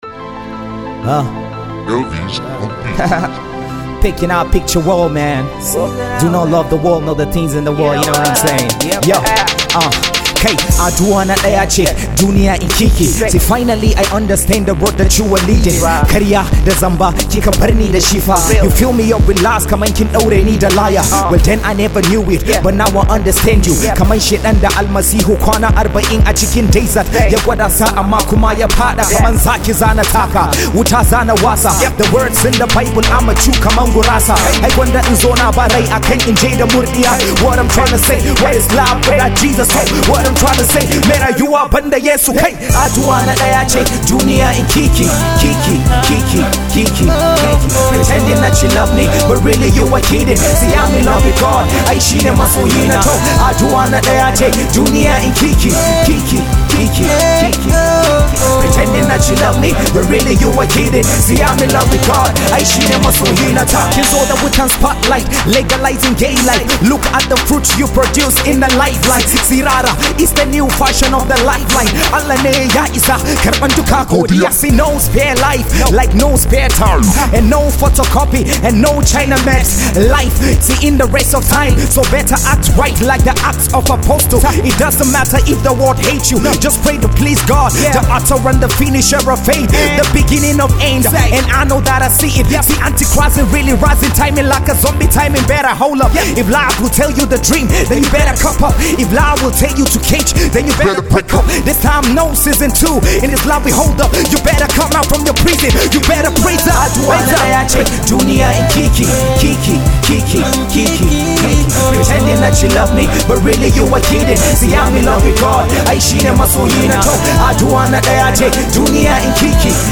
Gospel Rap